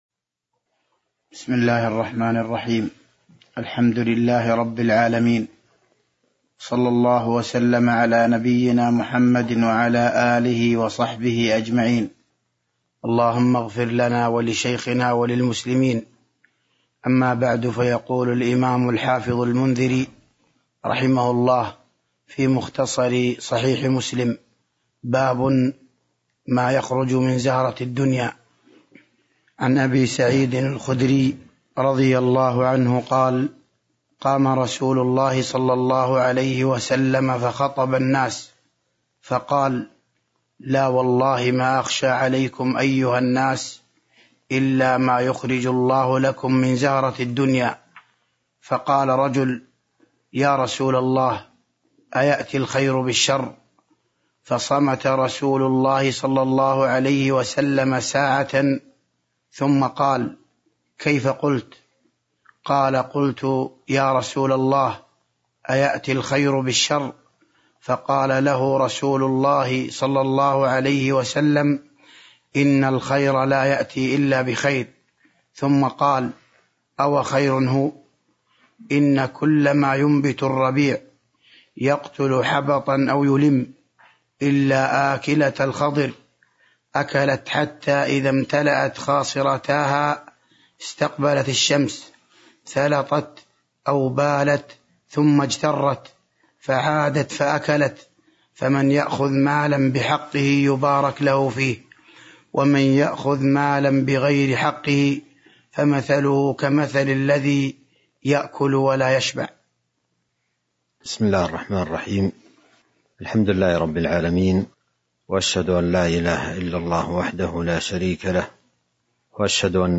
تاريخ النشر ١ شعبان ١٤٤٢ هـ المكان: المسجد النبوي الشيخ